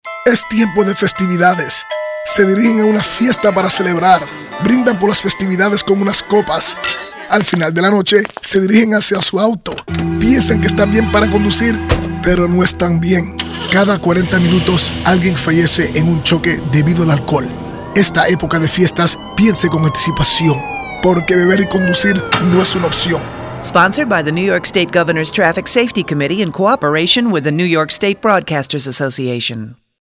Spanish Radio (transcripts below)